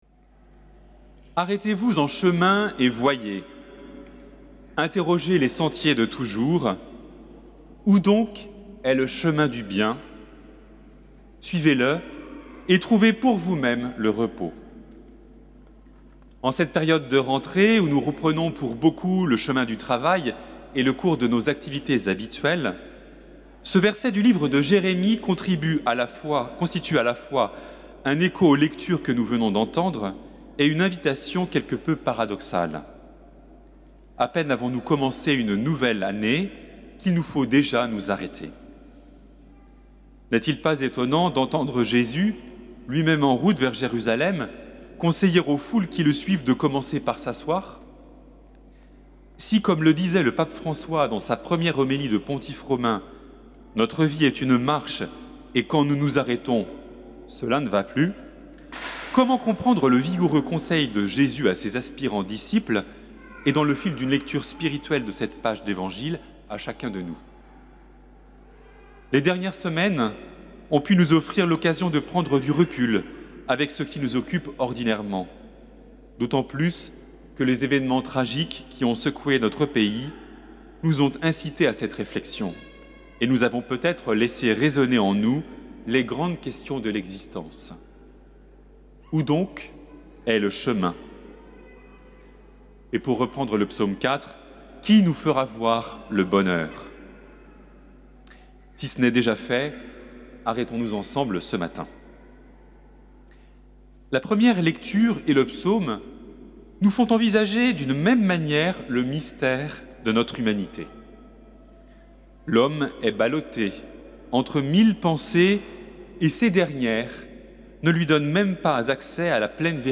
Homélie